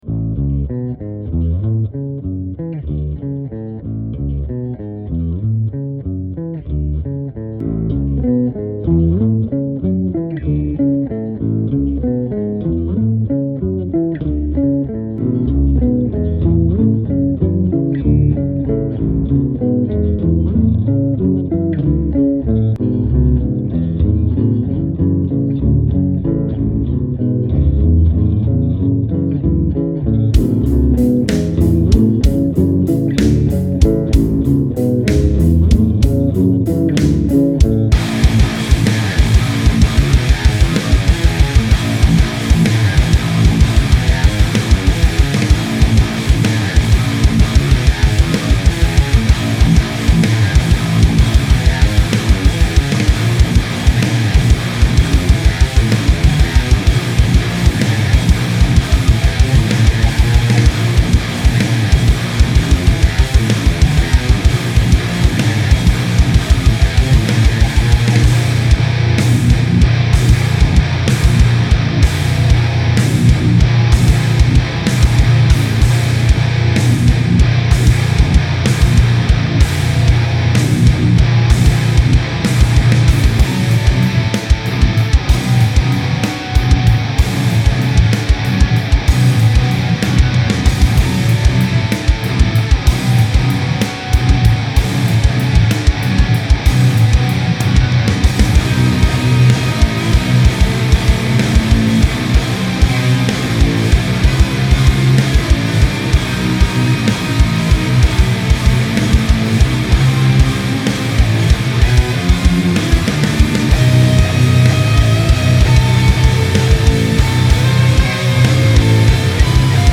Seven string stuff this time!